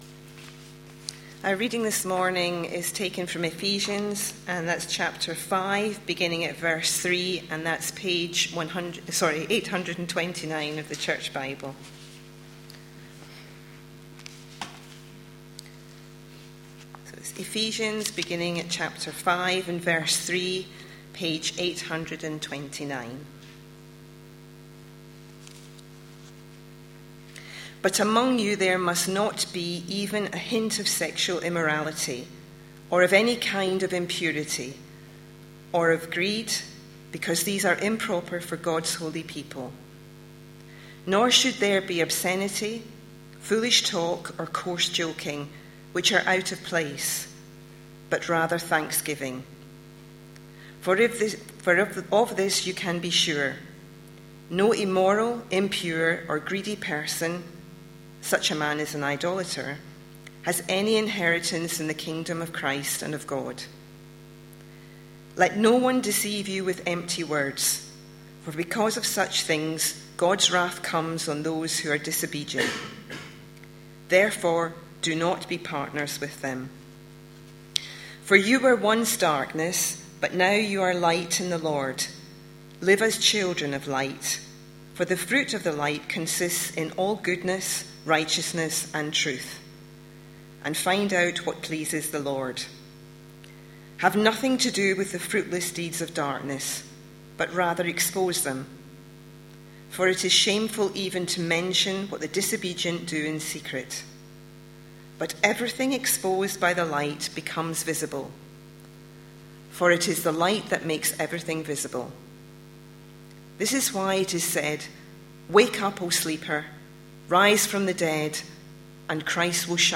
A sermon preached on 30th June, 2013, as part of our Ephesians series.